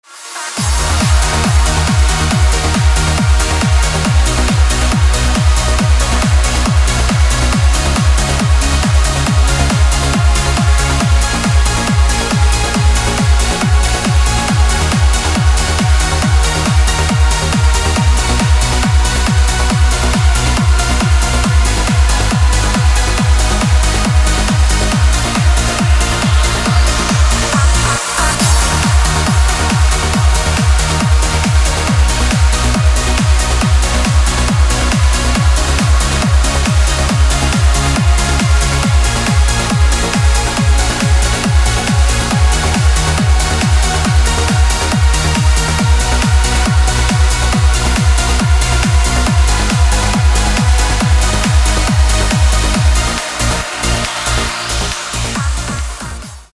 • Качество: 256, Stereo
громкие
dance
Electronic
электронная музыка
без слов
club
Trance
Uplifting trance